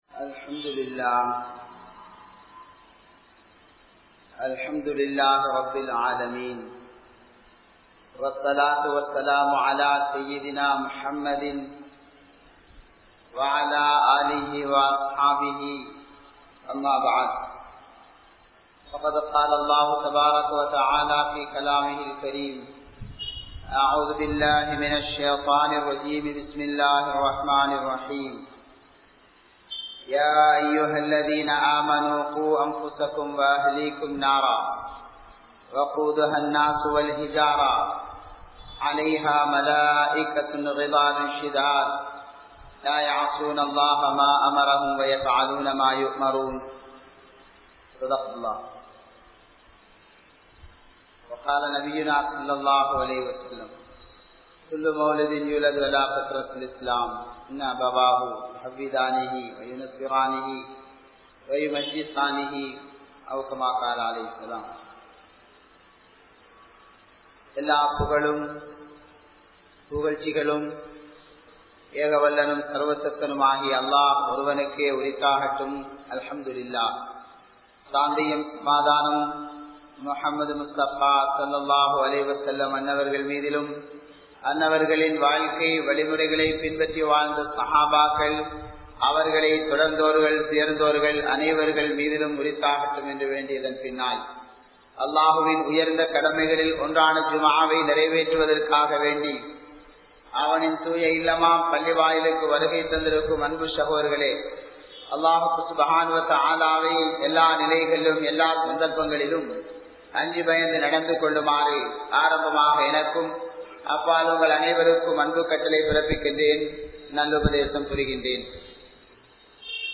Kulanthaihal Amaanithamaahum (குழந்தைகள் அமானிதமாகும்) | Audio Bayans | All Ceylon Muslim Youth Community | Addalaichenai